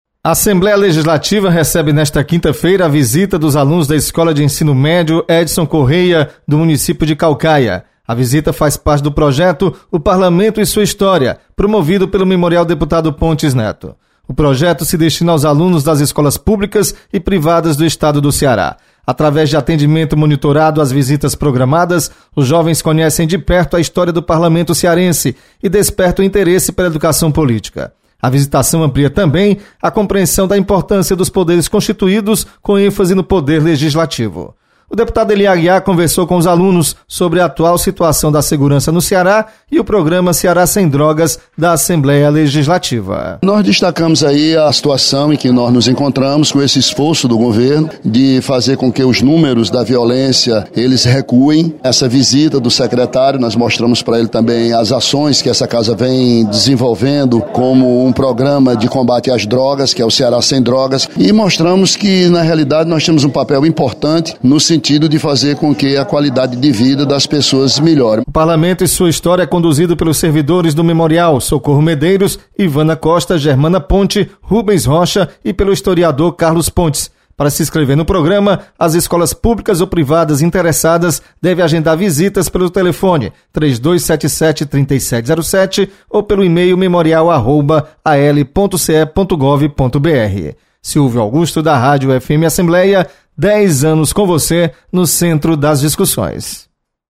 Estudantes de Caucaia conhecem o Memorial Pontes Neto. Repórter